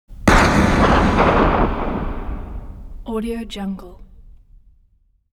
دانلود افکت صدای بازتاب تیراندازی
Sample rate 16-Bit Stereo, 44.1 kHz
Looped No